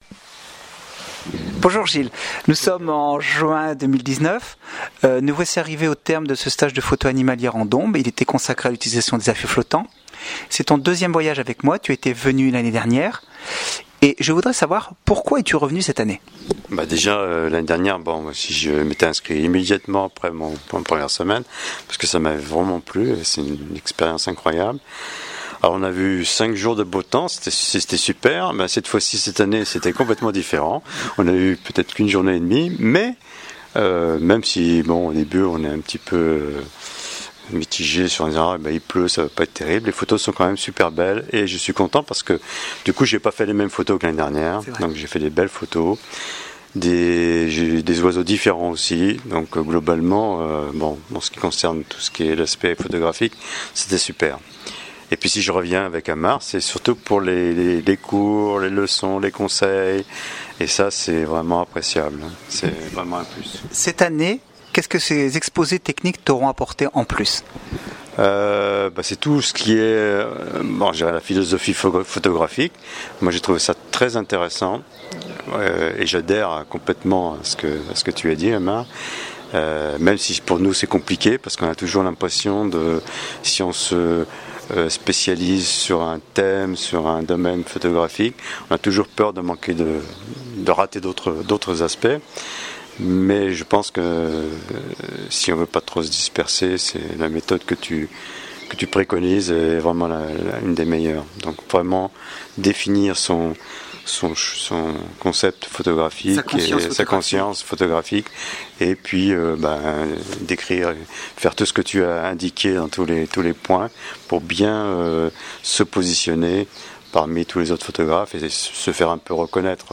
Le commentaire écrit et oral des participants